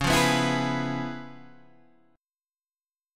C#7#9 chord {9 8 9 9 9 7} chord